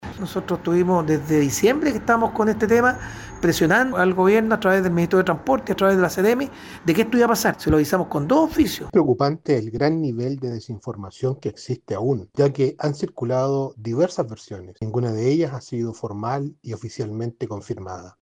El alcalde de Villa Alemana, Nelson Estay, junto al concejal Marcelo Góngora, calificaron la respuesta como tardía, señalando que se trata de un problema que se arrastra desde hace meses y que requiere una solución urgente para evitar un mayor impacto en la calidad de vida de los vecinos.
alcalde-villa-alemana.mp3